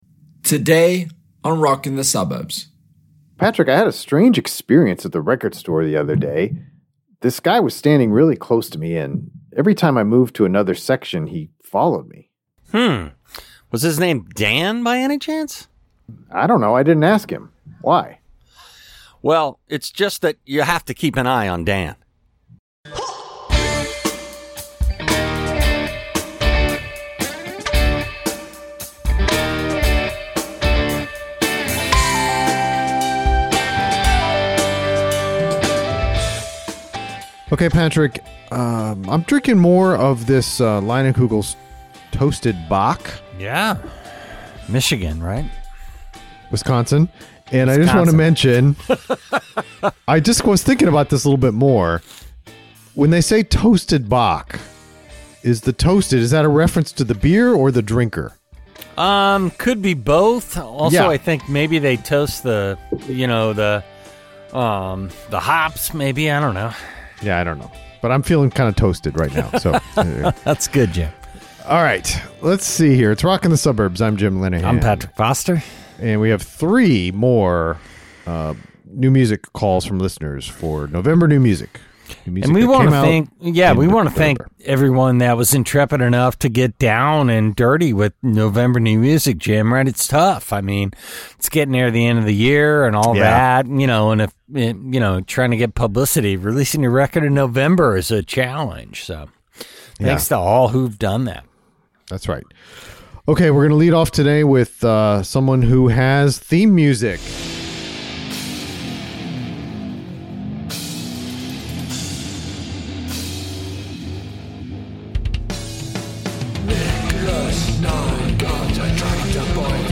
We conclude the November new music series with three listener calls.